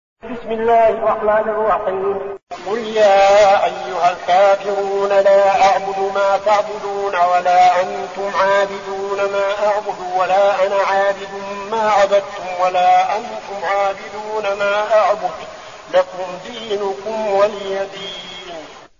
المكان: المسجد النبوي الشيخ: فضيلة الشيخ عبدالعزيز بن صالح فضيلة الشيخ عبدالعزيز بن صالح الكافرون